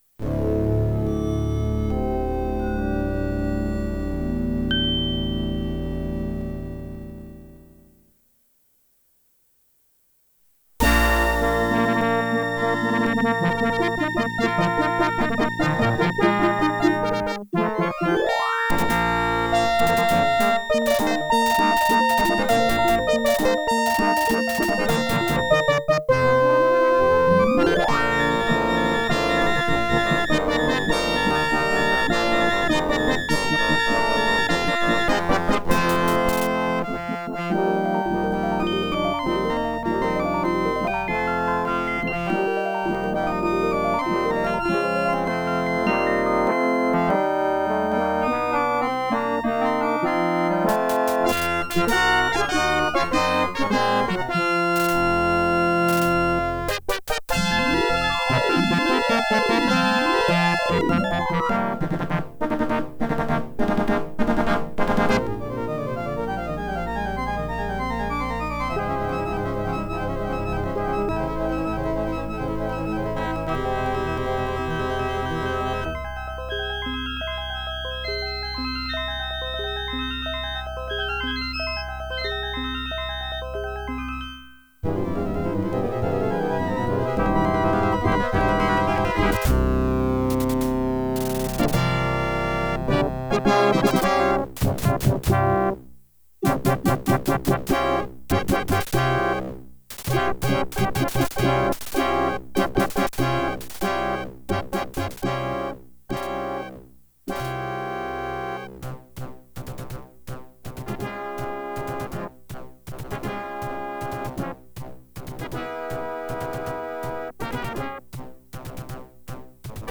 Configured for Sound Blaster Pro.